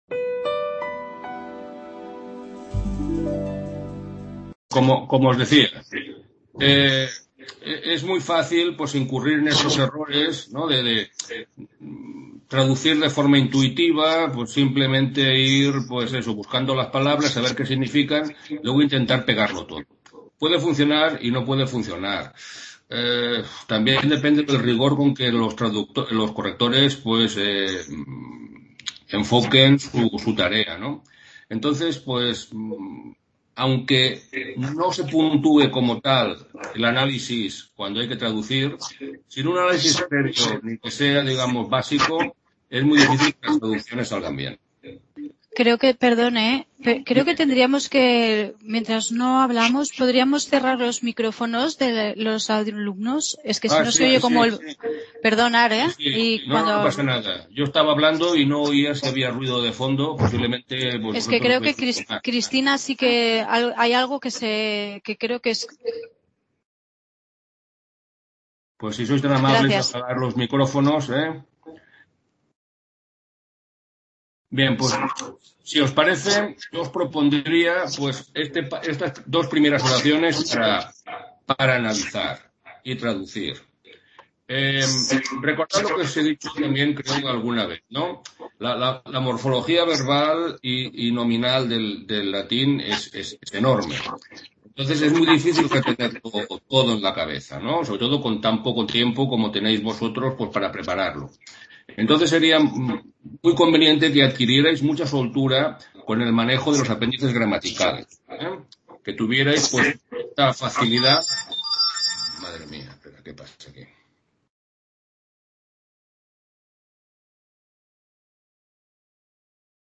Tutoría latín para hispanistas 26/03/2021 | Repositorio Digital